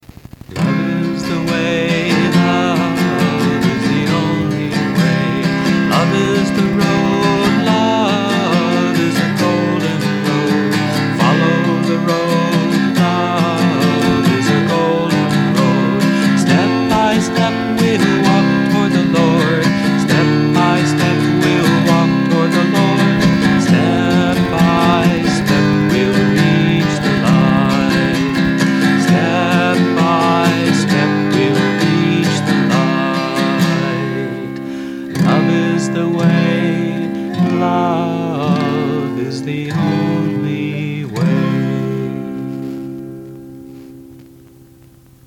1. Devotional Songs
Major (Shankarabharanam / Bilawal)
8 Beat / Keherwa / Adi
Medium Fast